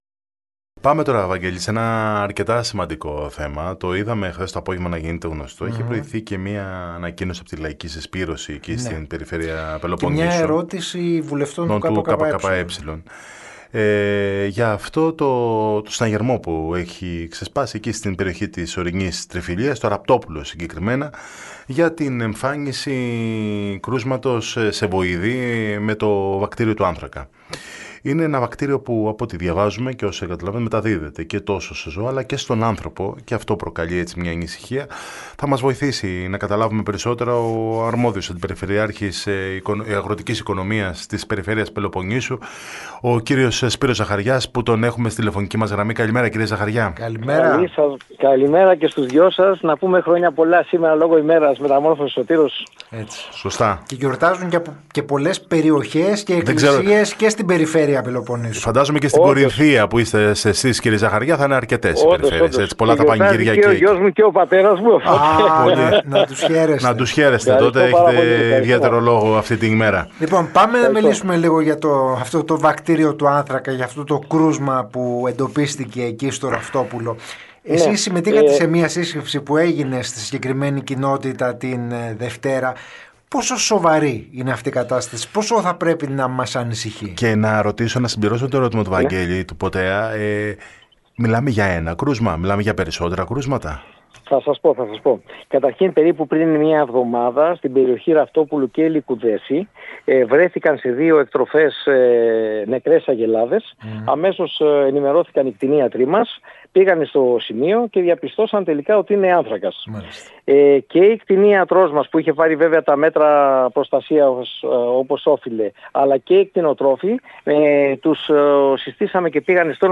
Ο Αντιπεριφερειάρχης Αγροτικής Ανάπτυξης και Κτηνιατρικής της Περιφέρειας Πελοποννήσου, Σπύρος Ζαχαριάς, μίλησε σήμερα το πρωί στο ραδιόφωνο της ΕΡΤ Καλαμάτας και στην εκπομπή «Πρωινό στον Αέρα», με αφορμή την εμφάνιση επιβεβαιωμένων κρουσμάτων βακτηρίου άνθρακα σε κτηνοτροφικές μονάδες της Τριφυλίας.